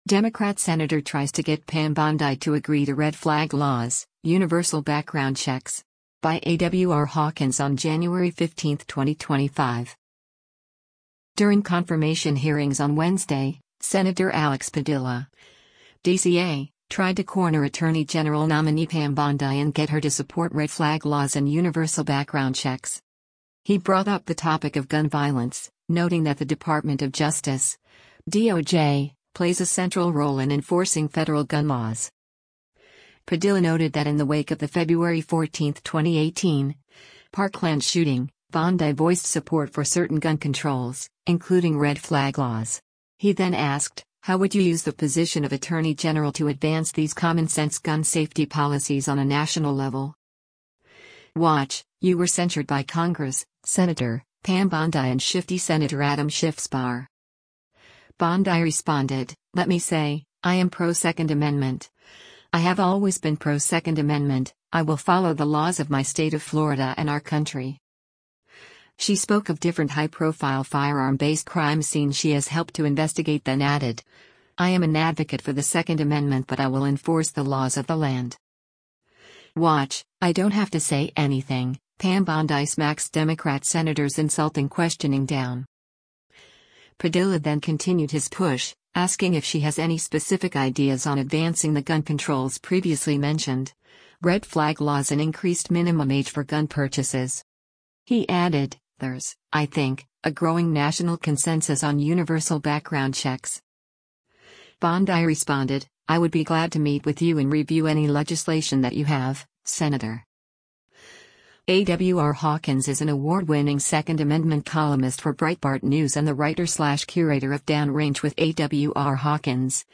During confirmation hearings on Wednesday, Sen. Alex Padilla (D-CA) tried to corner Attorney General nominee Pam Bondi and get her to support red flag laws and universal background checks.